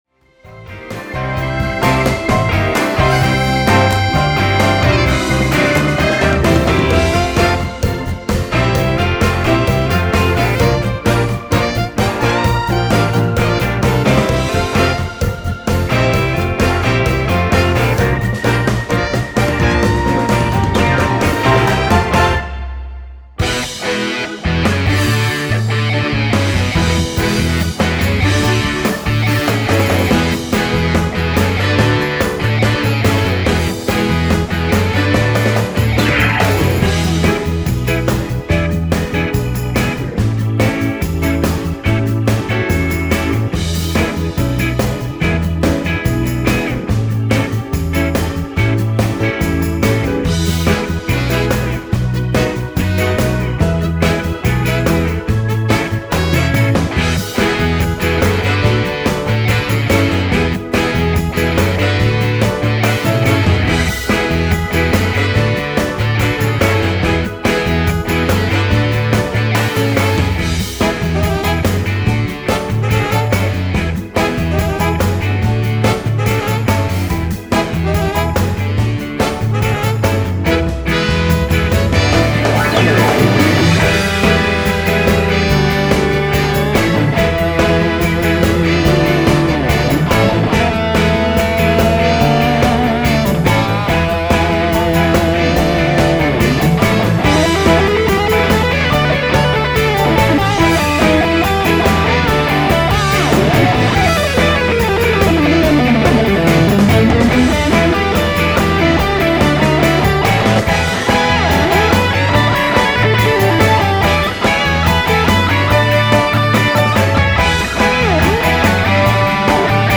Fully produced rehearsal track